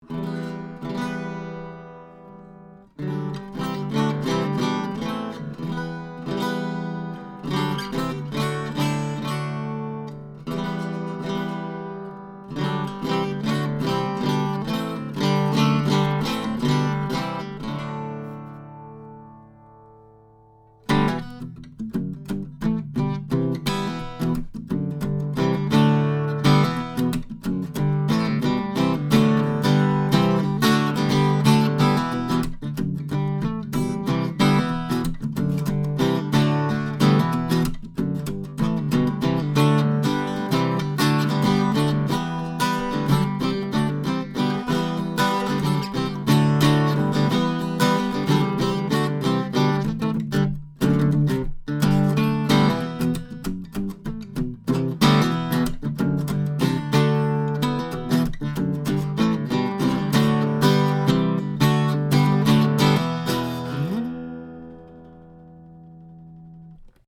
Archtop
This is a fantastic hand-made archtop guitar with beautiful full-range tone, excellent volume and projectipon, along with nice, low action. 1930s Gibsons, even the low-end models like the L50 currently sell for $1,500.
Recorded with a couple of prototype TAB Funkenwerk / German Masterworks CG•OA-1 condenser mics into a Trident 88 recording console using Metric Halo ULN8 converters.